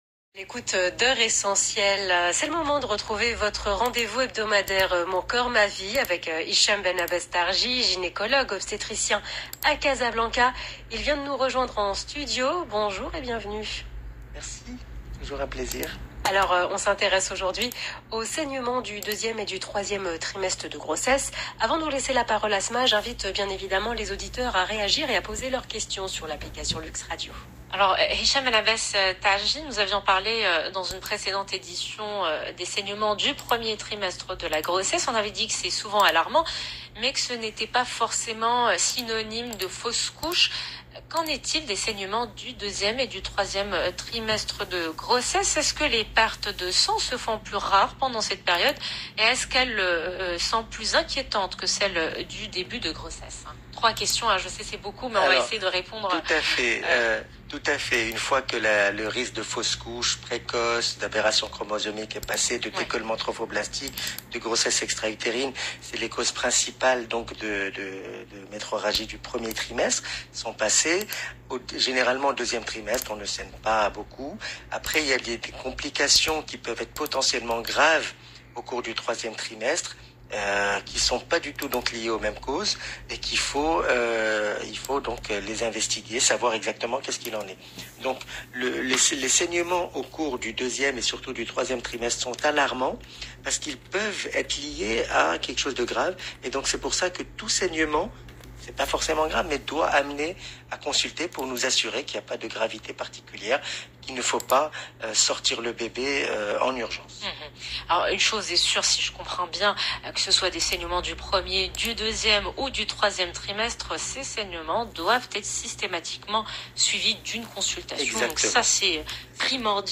Essai d’explications dans cette interview de l’Heure Essentielle sur LUXE RADIO du 1er juin 2021